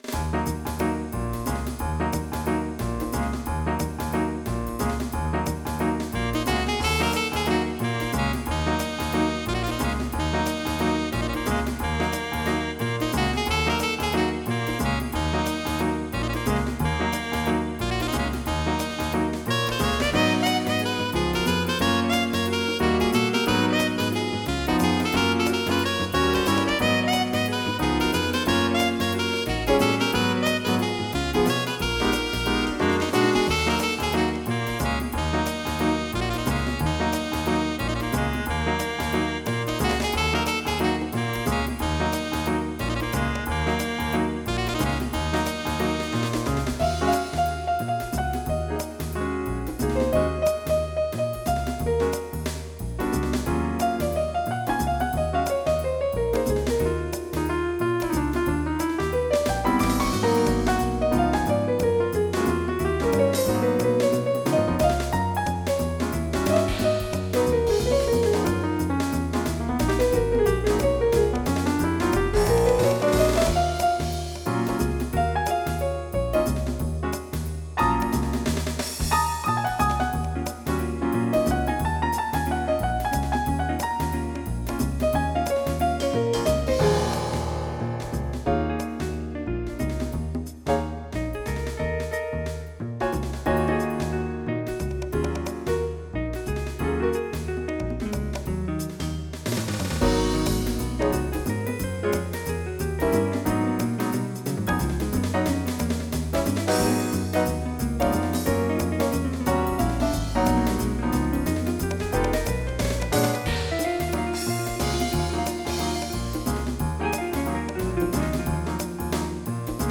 Jazz
MIDI Music File
General MIDI